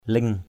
/lɪŋ/ (d.) nướu, lợi = gencive. gums. klao mboh ling _k*< _OH l{U cười hở lợi.